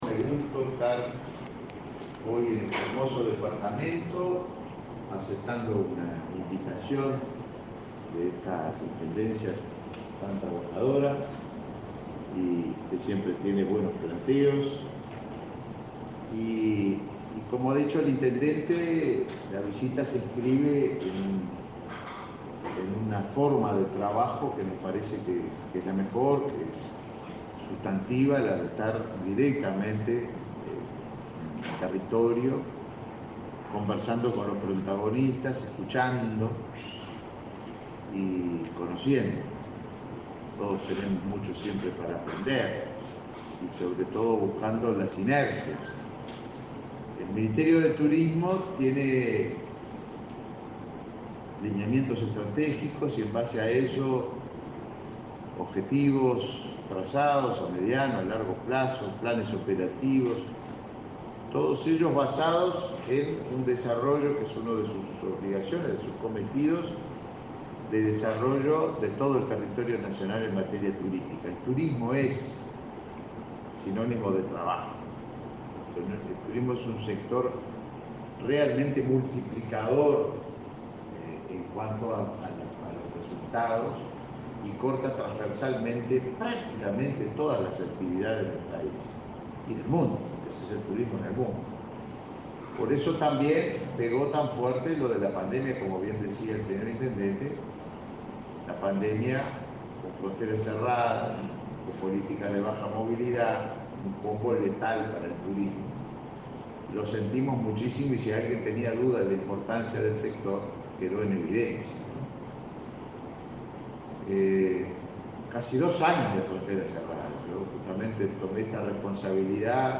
Conferencia del ministro de Turismo, Tabaré Viera
Conferencia del ministro de Turismo, Tabaré Viera 10/03/2022 Compartir Facebook X Copiar enlace WhatsApp LinkedIn El ministro de Turismo, Tabaré Viera, visitó este jueves 10 el departamento de Florida, donde brindó una conferencia de prensa luego de presentar el llamado a fondos concursables para desarrollo de la oferta de turismo en el departamento.